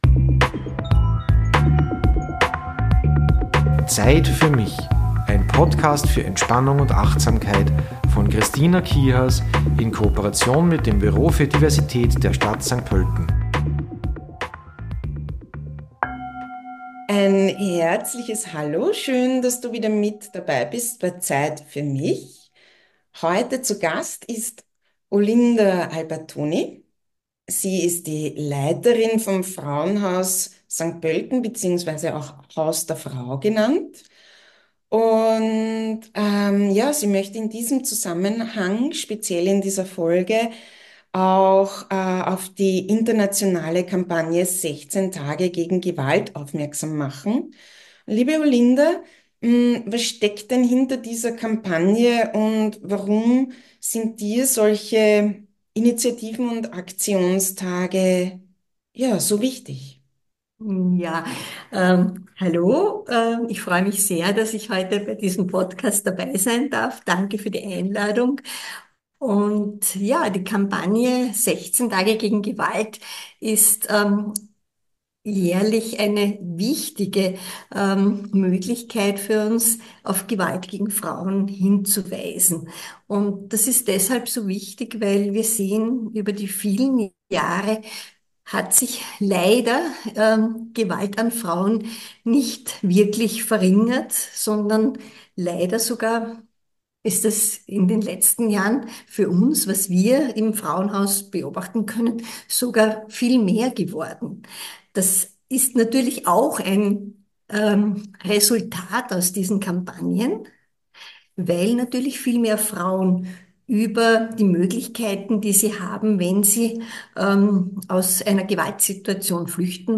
Mit ihr durfte ich ein sehr aufklärendes Gespräch führen.